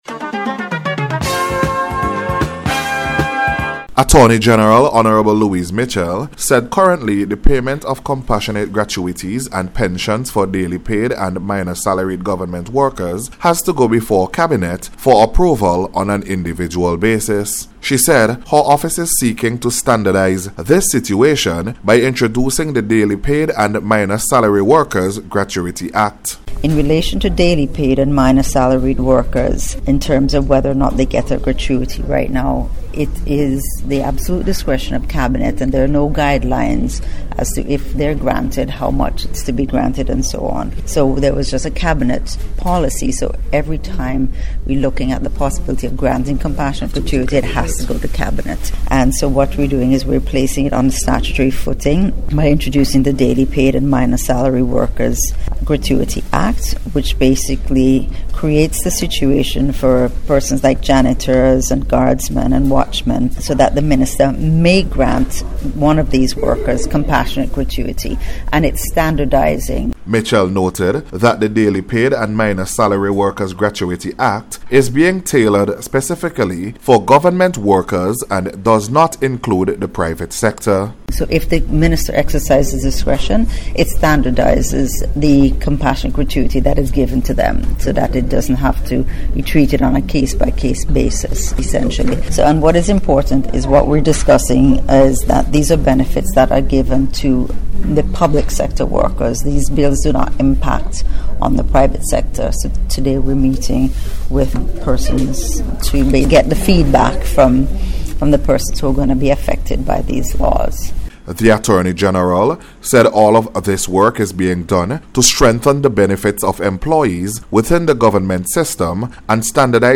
LAW-REFORM-REPORT.mp3